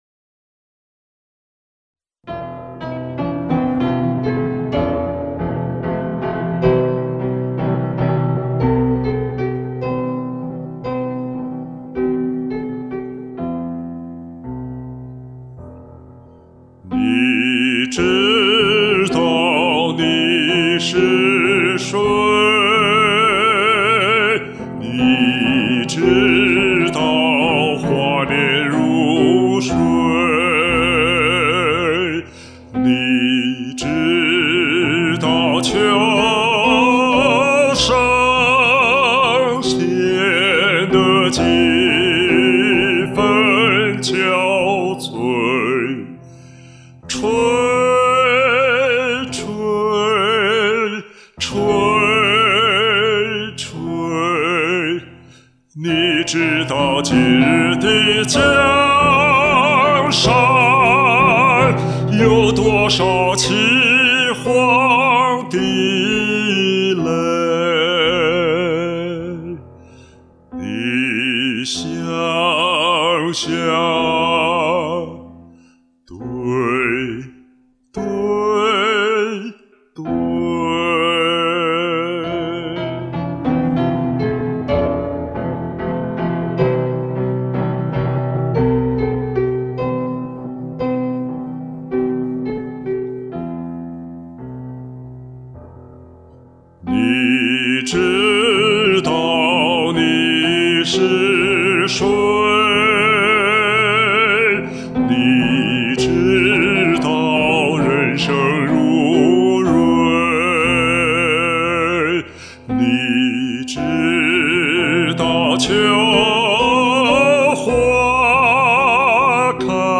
这个伴奏降调后质量很差，否则会再降一两个key。